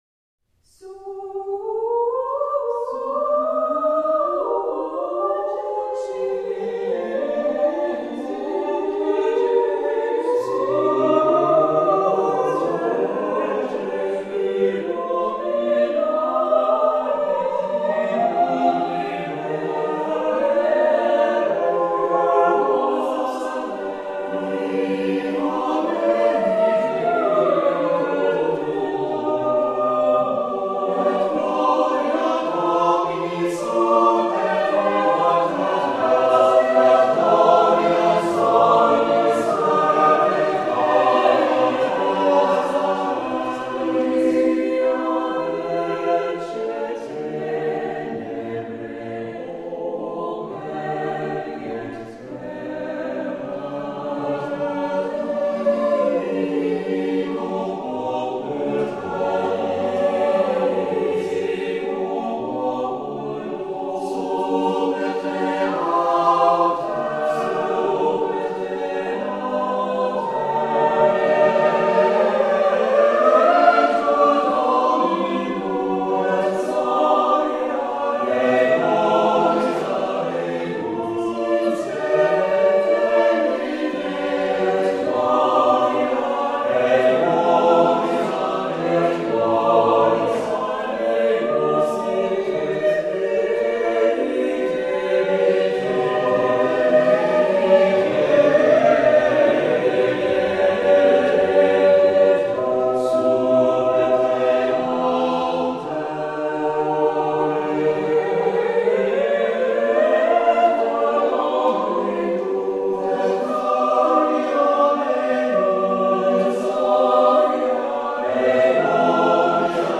Early music vocal ensemble.
Renaissance motets for the Christmas season
Classical, Renaissance, Choral